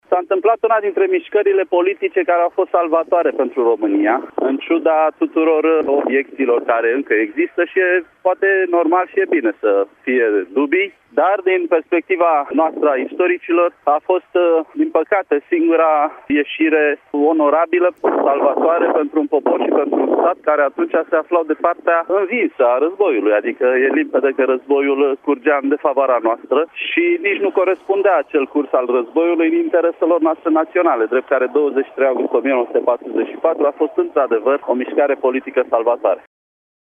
Istoricul Adrian Cioroianu apreciază că decizia luată la acel moment a fost una salvatoare.